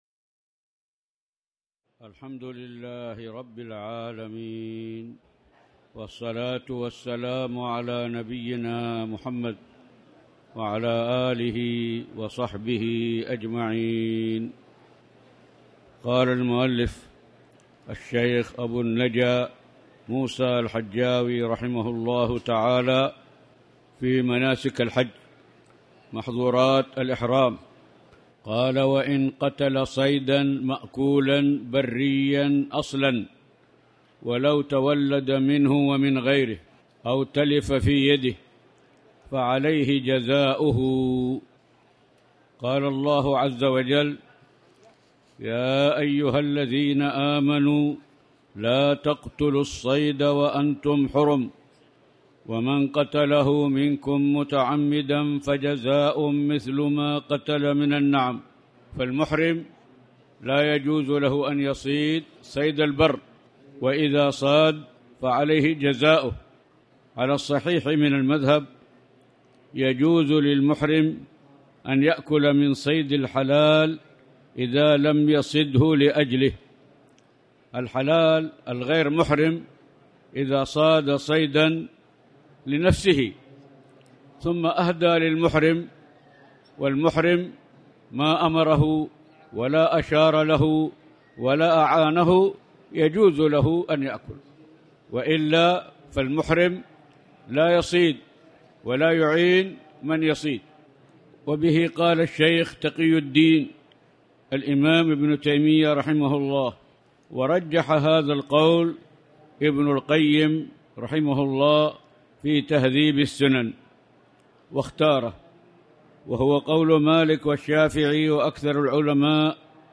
تاريخ النشر ١٦ ذو القعدة ١٤٣٨ هـ المكان: المسجد الحرام الشيخ